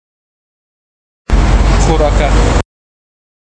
uitspraak farsi